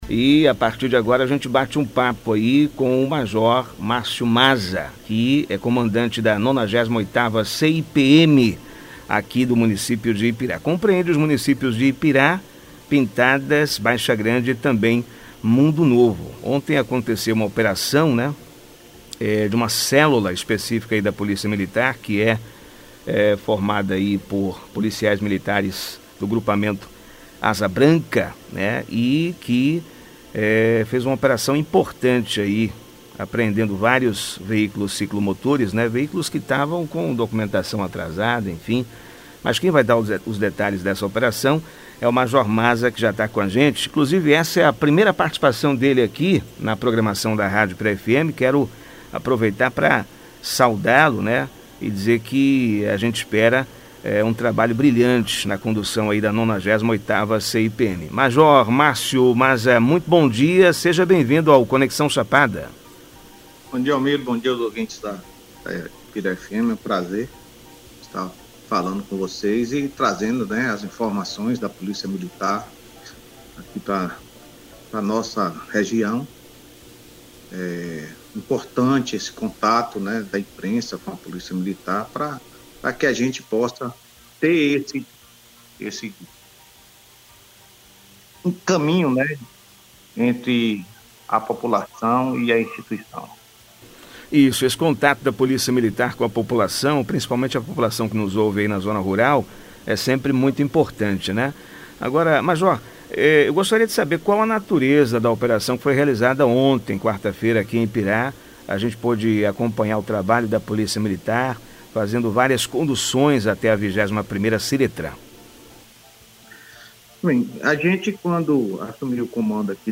Conexão Chapada Entrevista